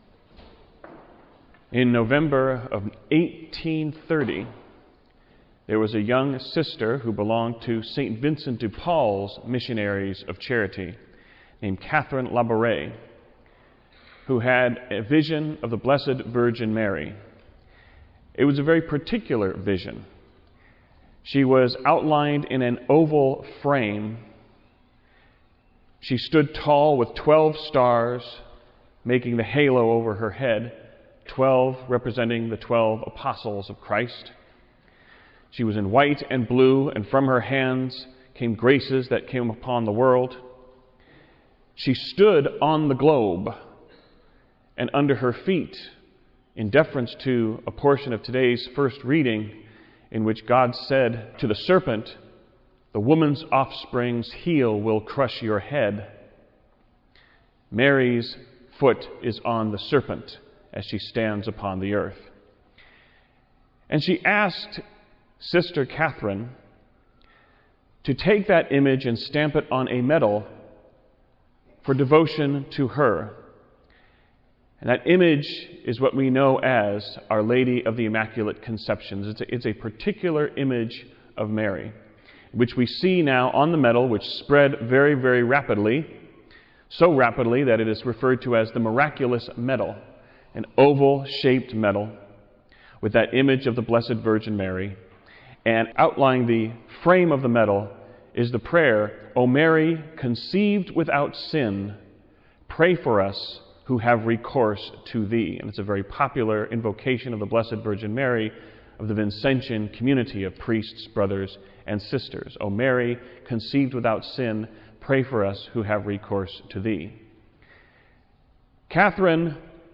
Homily-ImmaculateConceptionElection2016.wav